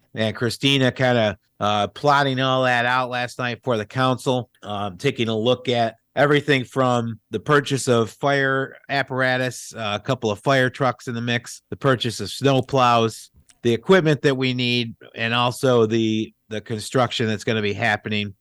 City Clerk Chuck Mason explains what that entails: